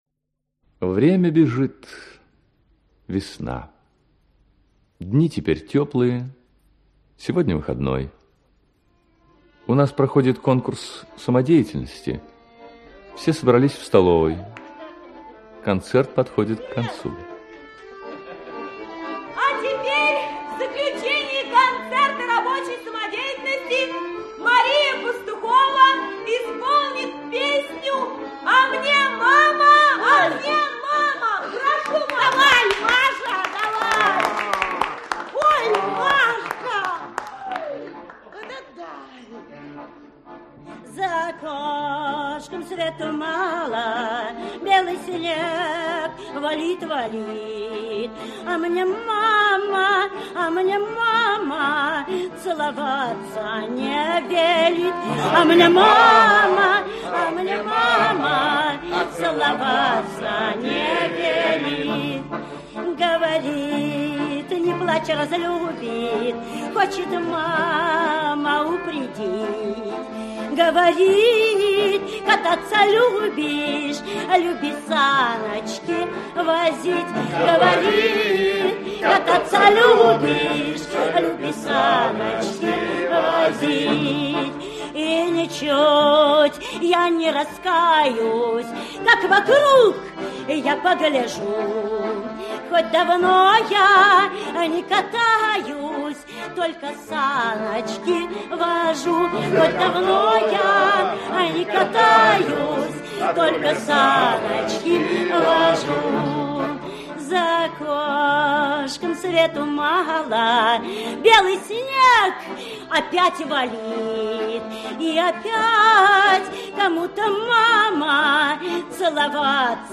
Аудиокнига Здравствуй, Крымов! Часть 2 | Библиотека аудиокниг
Часть 2 Автор Роальд Викторович Назаров Читает аудиокнигу Актерский коллектив.